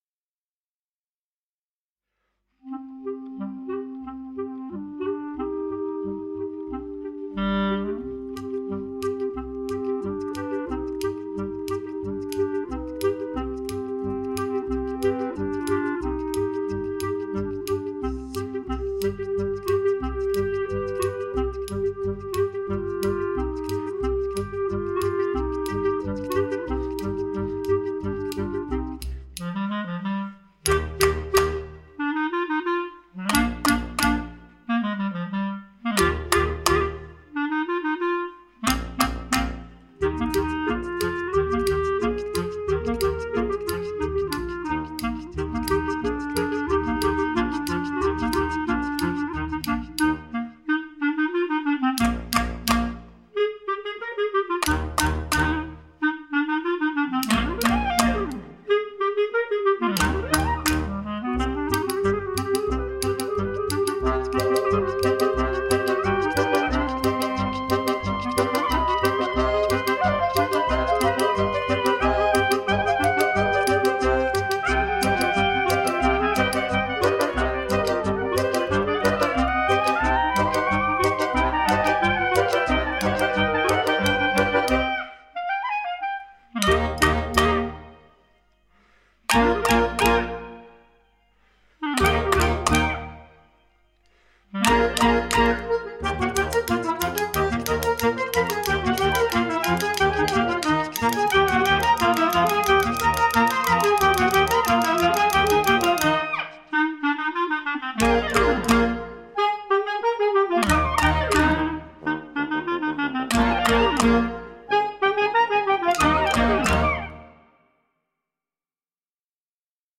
Un play-back pour jouer ce charmant thème klezmer qui sent bon la Pologne.
patsh-tantz-play-along-1.mp3